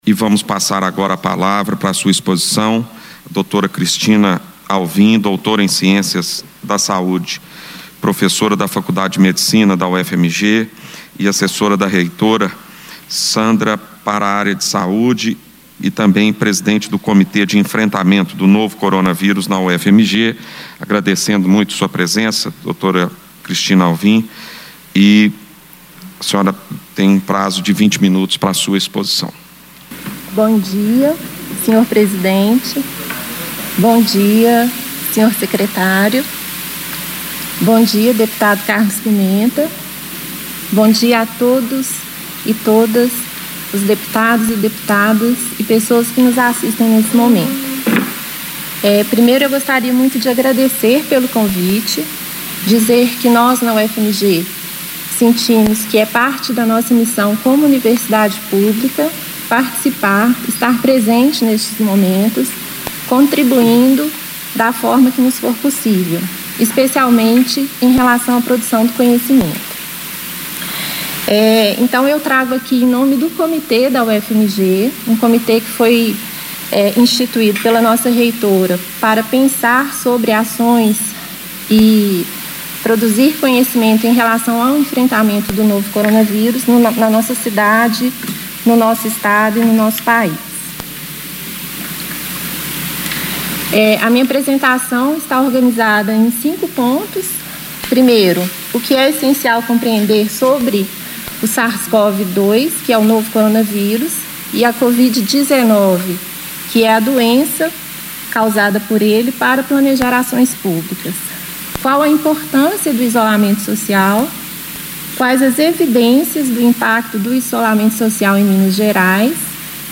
Na íntegra do pronunciamento durante audiência no Plenário, a professora e pediatra esclarece pontos de vista e protocolos necessários para conciliar retomada da atividade econômica em tempos de pandemia da Covid-19.
Discursos e Palestras